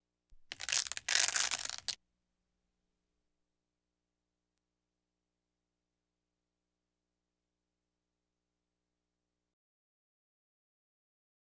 Velcro Open Sound Effect
Download a high-quality velcro open sound effect.
velcro-open-1.wav